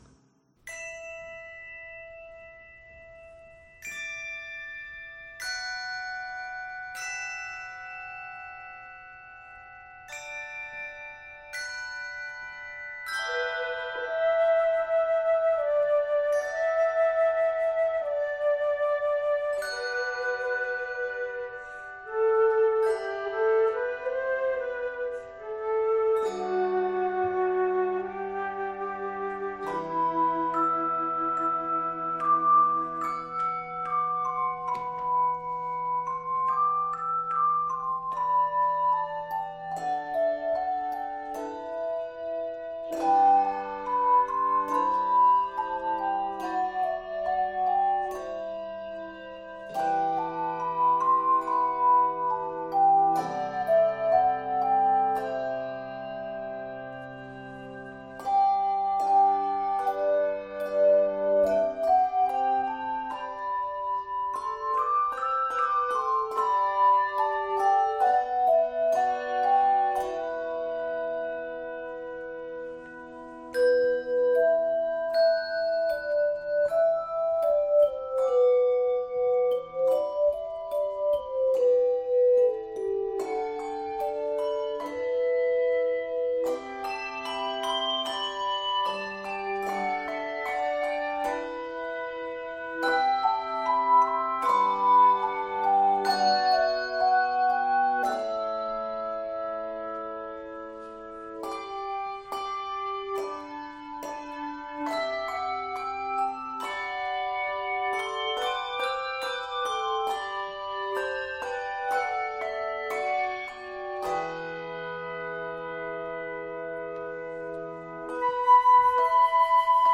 lyrical medley
Flowing lines and delicate accompaniment
Keys of e minor and G Major.